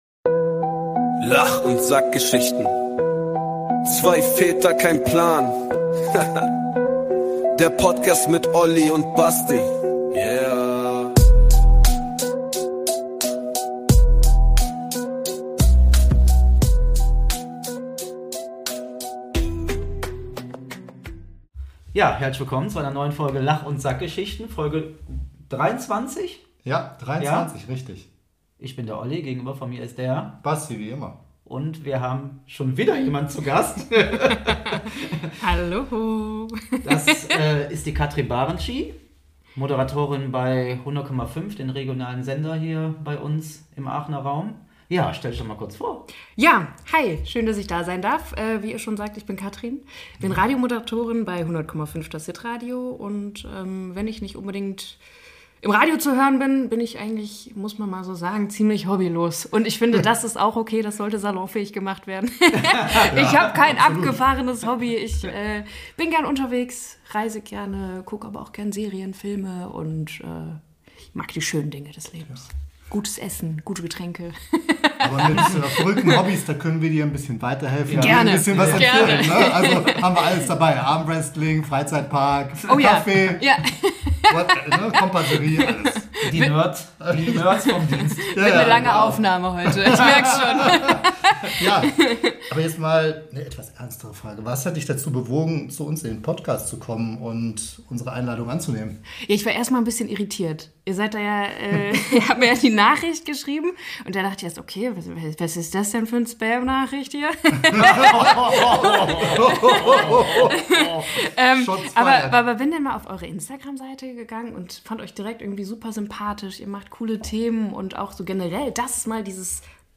Herausgekommen ist eine von sehr viel Humor geprägte Folge.